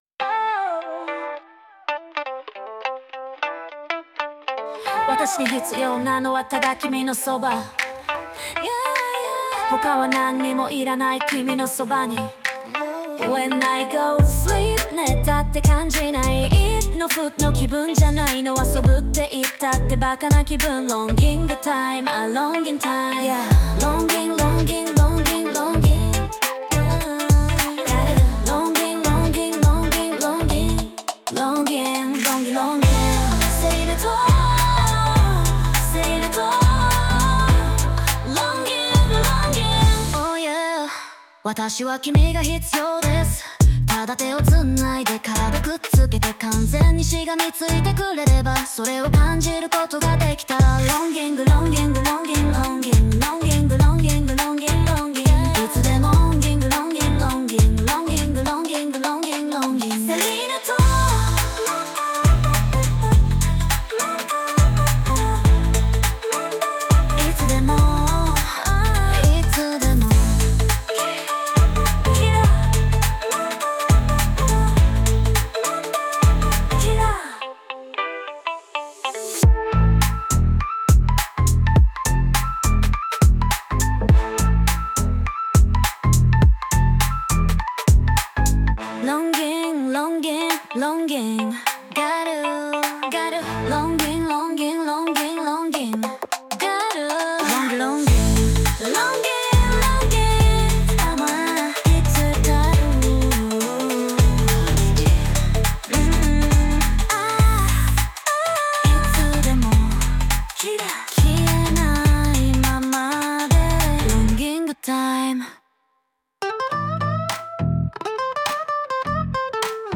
サビ前の導入部分が、まさかの「ロンギング」連呼！
最初からこういう曲だったみたいに聞こえる。